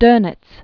(dœnĭts), Karl 1891-1980.